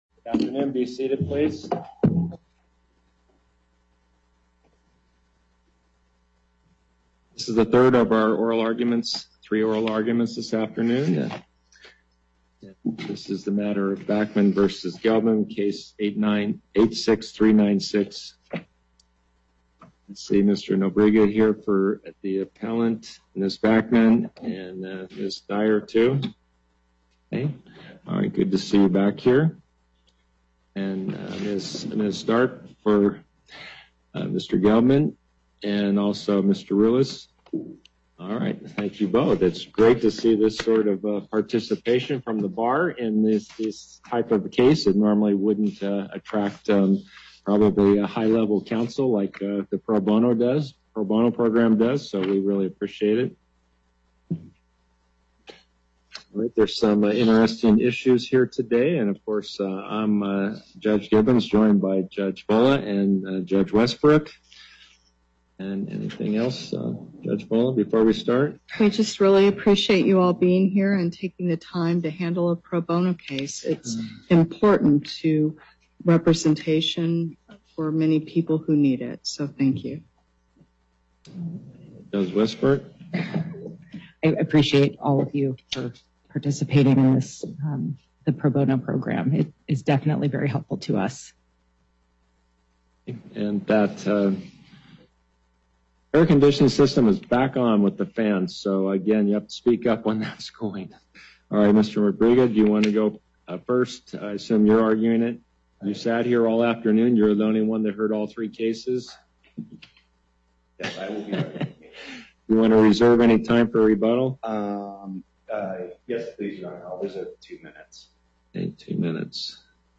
Before the Court of Appeals, Chief Judge Gibbons presiding